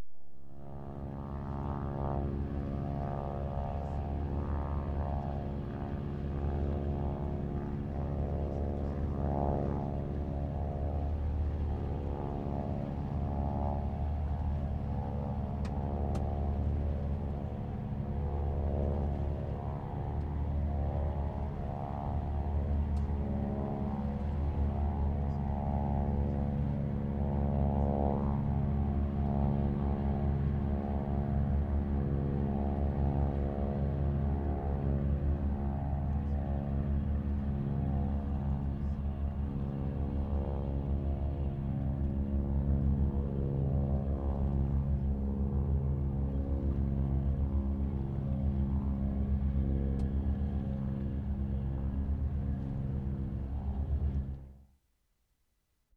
WATERFRONT / CP WHARF Sept. 13, 1972
1. HARBOUR AMBIENCE 3'35"
7. Begins with seaplane, otherwise general ambience.